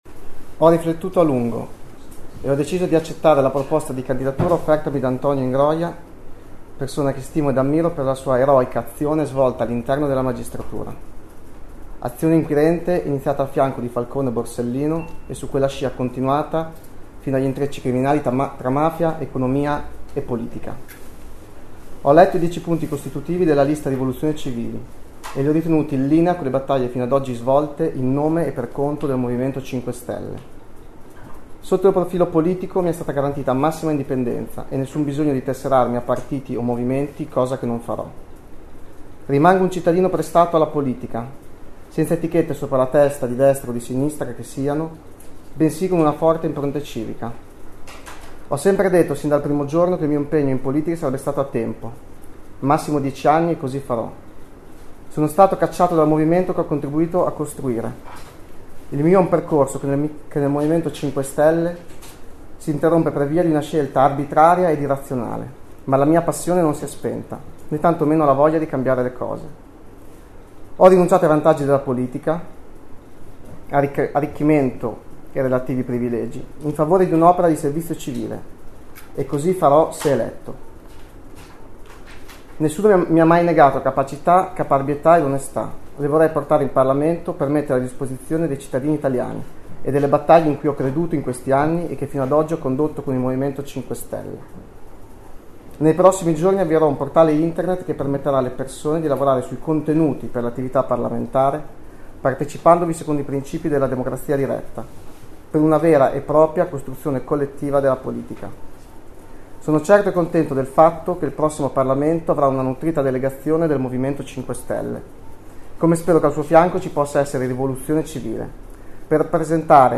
“Mi è stata garantita massima indipendenza” ha detto Favia che ha letto, con gli occhi rossi incollati sul foglio e la voce incerta, la nota con cui dice sì all’ex pm.